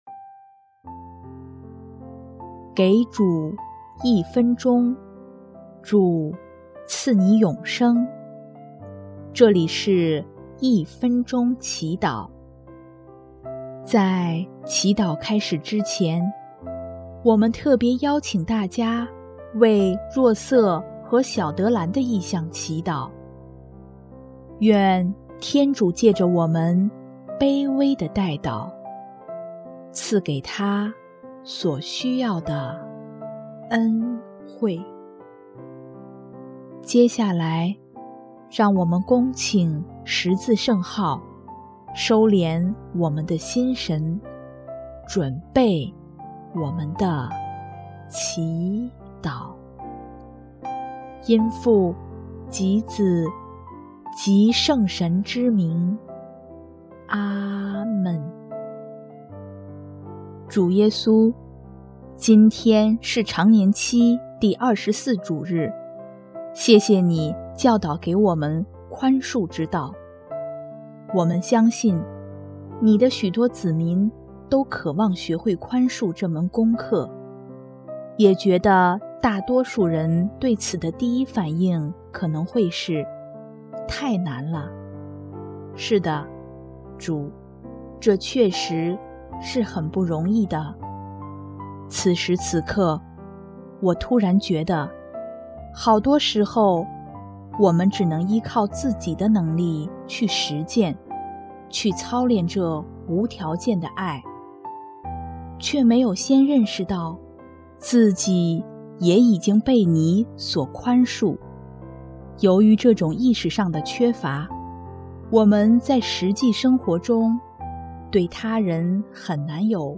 【一分钟祈祷】|9月17日 操练宽恕